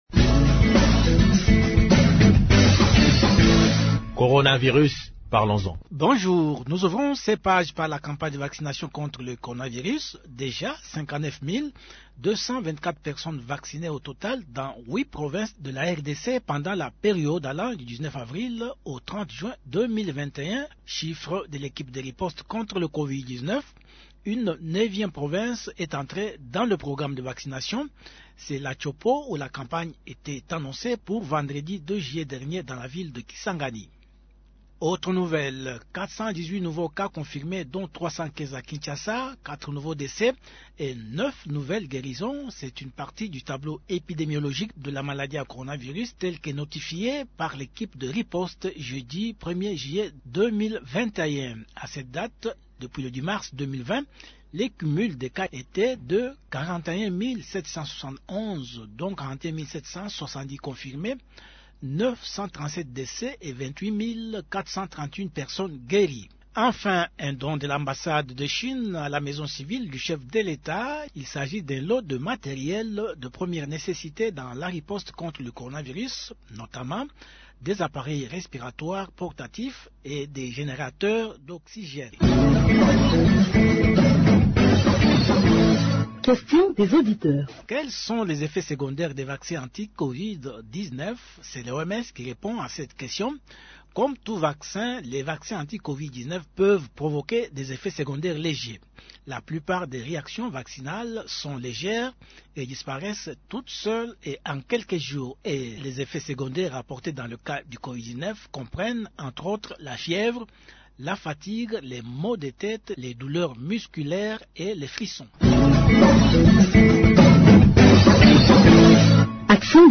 Un extrait du message de Mme Nana Manuanina, ministre près la présidence de la République.